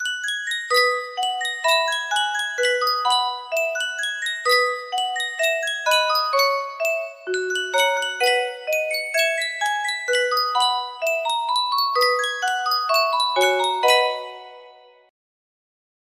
Yunsheng Music Box - Danny Boy 5924 music box melody
Full range 60